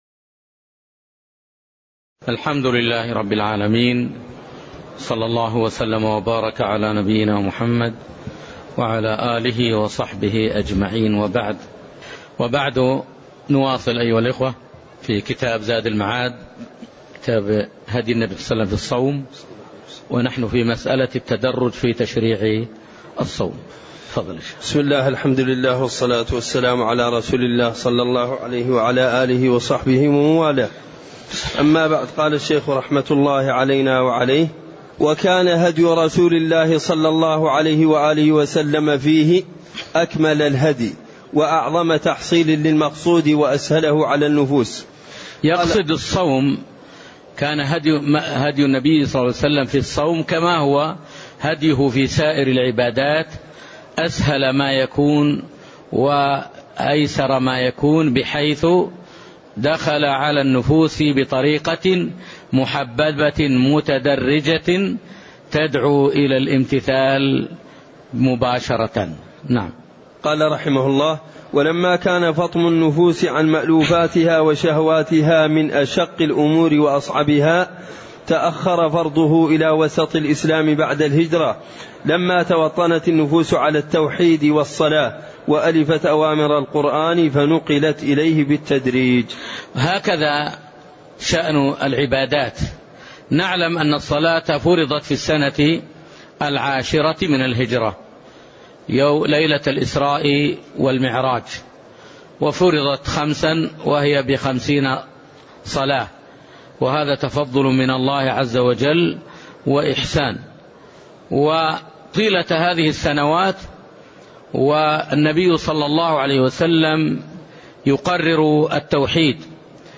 تاريخ النشر ٦ رمضان ١٤٣٠ هـ المكان: المسجد النبوي الشيخ